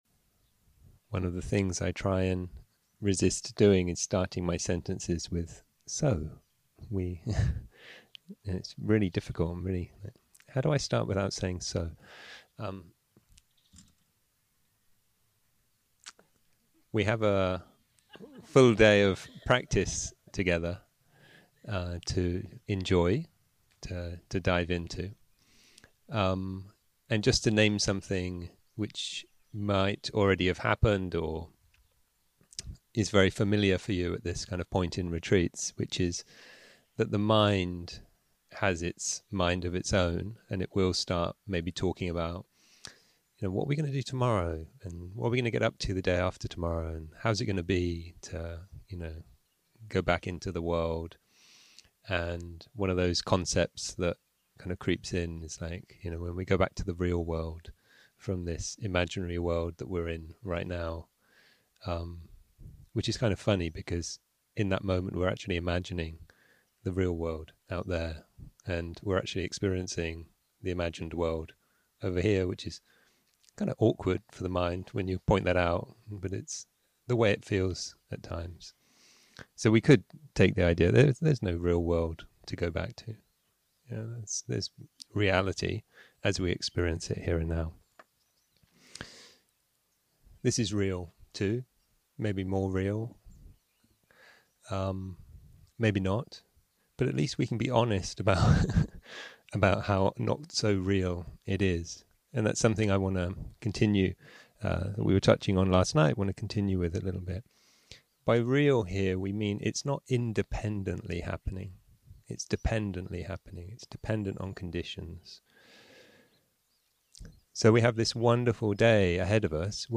יום 6 - הקלטה 14 - בוקר - הנחיות למדיטציה - Equanimity Transcends 0:00 0:00 סוג ההקלטה: Dharma type: Guided meditation שפת ההקלטה: Dharma talk language: English